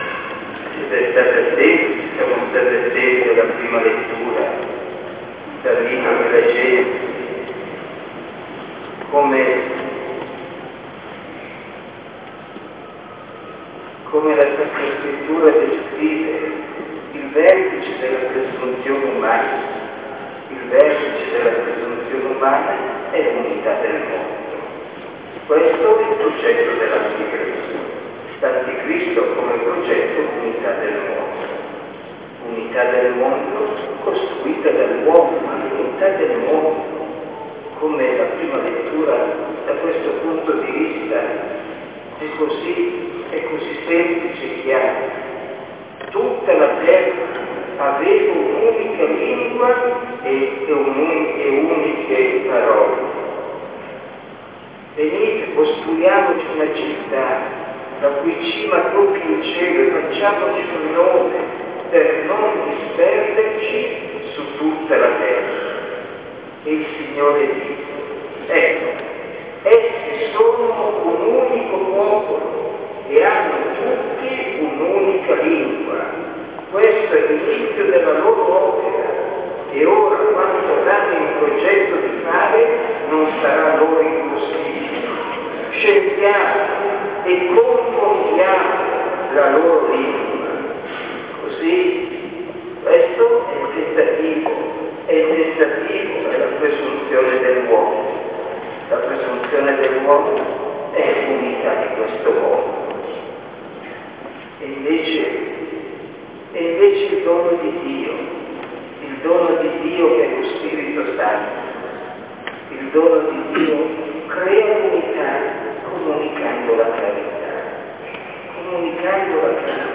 OMELIA